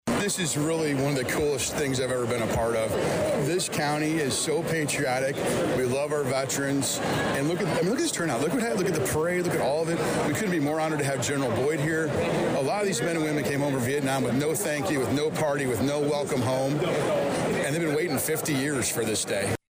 Saturday’s ceremony at Georgetown-Ridge Farm High School was moved indoors due to the wet weather from Friday.